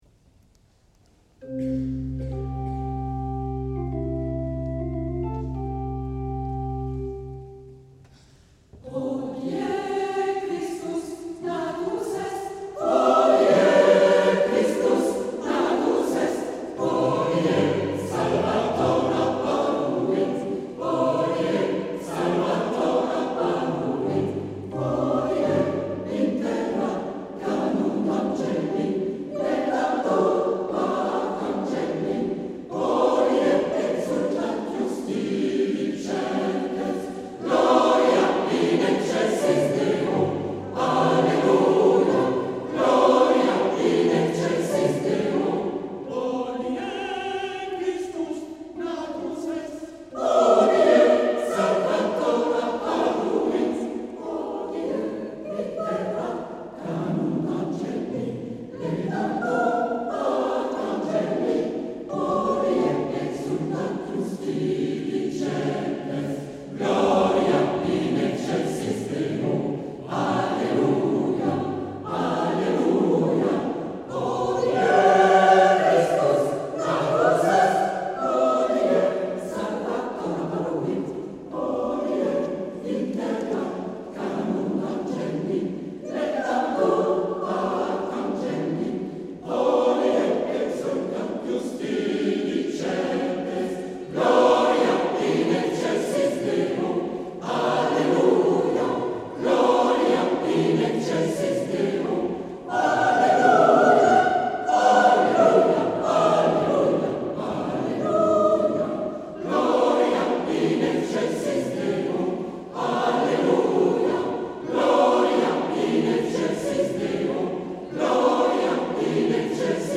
Concert du 2 décembre 2018 à Delémont